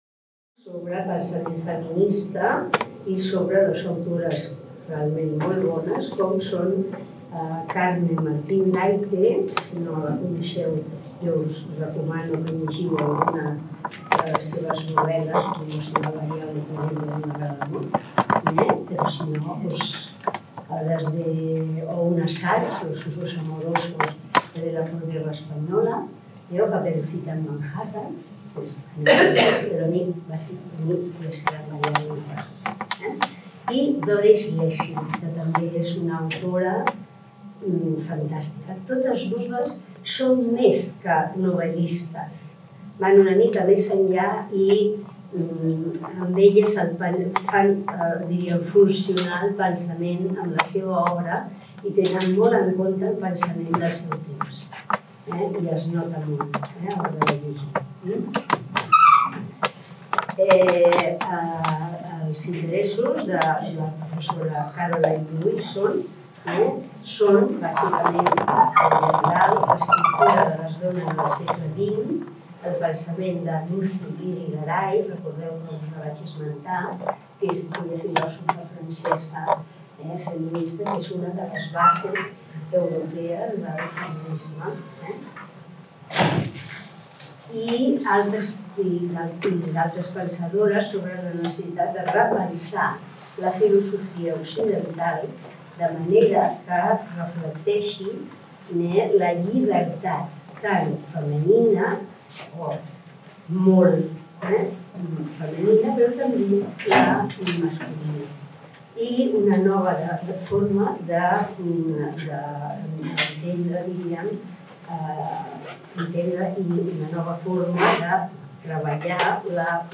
en el marc del XXII Seminari Internacional de Cultura Escrita i Visual Josepa Arnall Juan